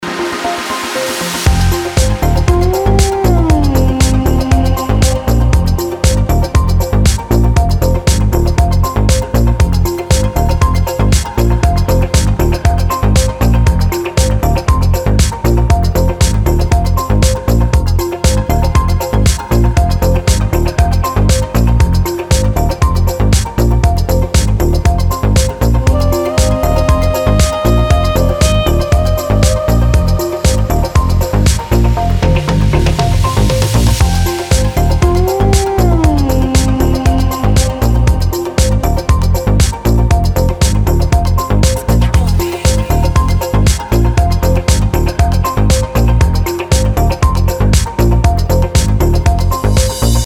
ритмичные
спокойные
Downtempo
инструментальные
Саксофон
Стиль: nu disco